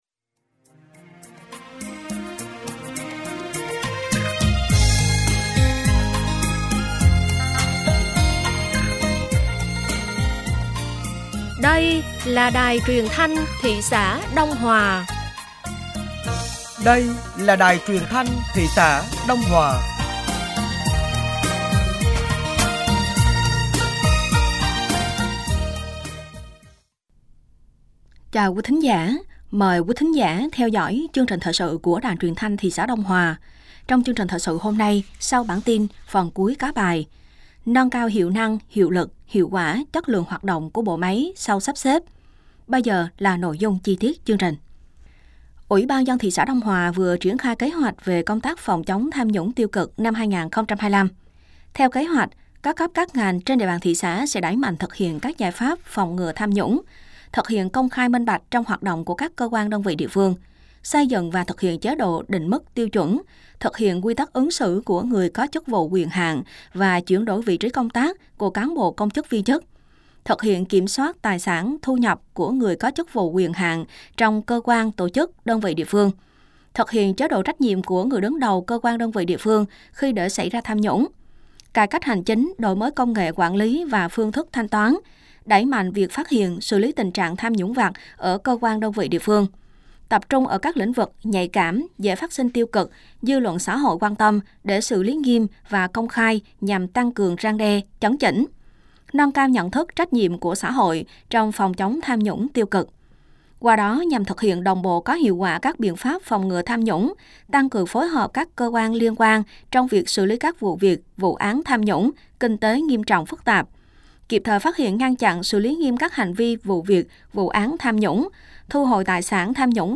Thời sự tối ngày 18 và sáng ngày 19 tháng 3 năm 2025